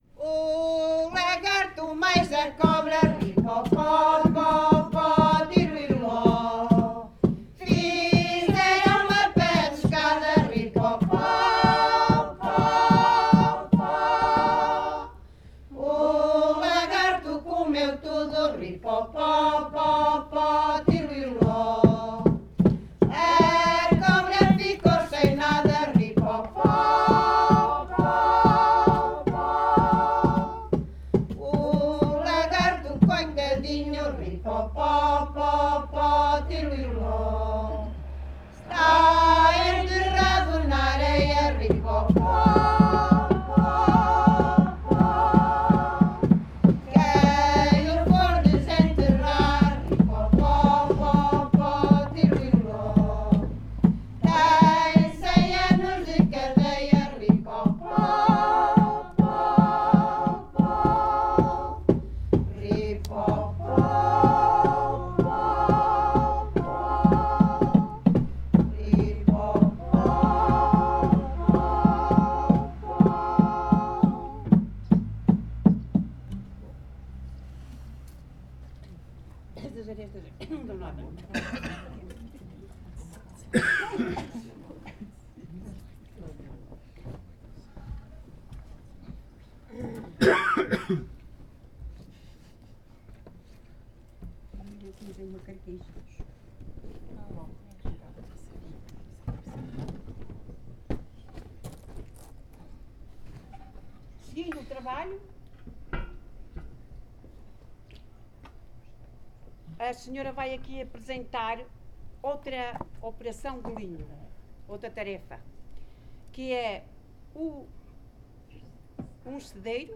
NODAR.00679 – Grupo Etnográfico de Trajes e Cantares do Linho durante o encontro SoCCos em Portugal – Tasca (Várzea de Calde, Viseu)